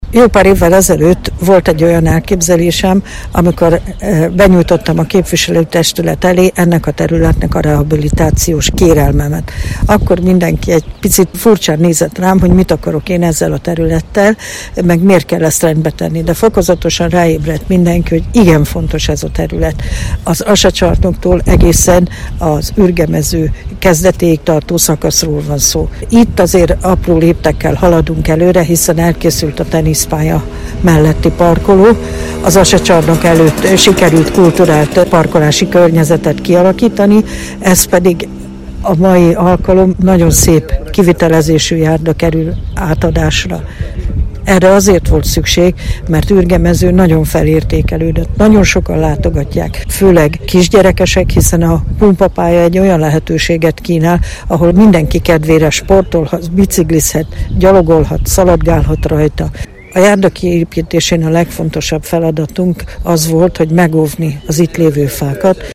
Kudorné Szanyi Katalin önkormányzati képviselő a műszaki átadáskor beszélt a részletekről.